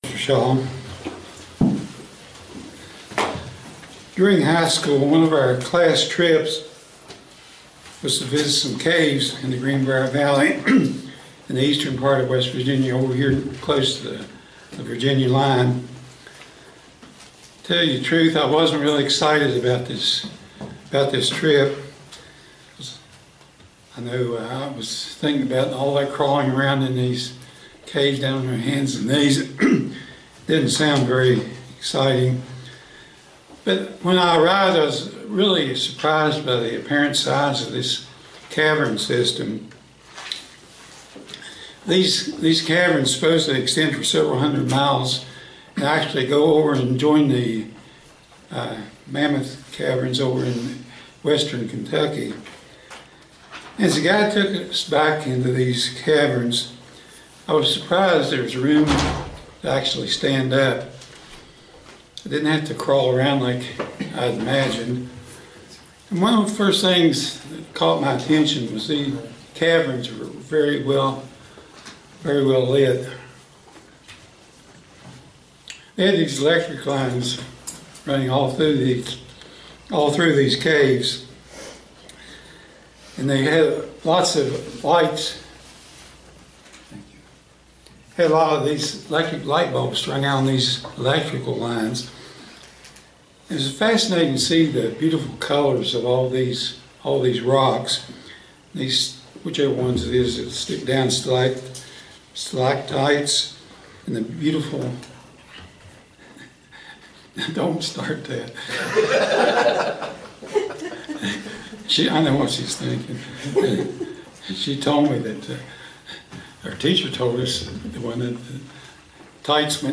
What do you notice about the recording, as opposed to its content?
Given in Roanoke, VA